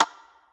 spinwheel_tick_04.ogg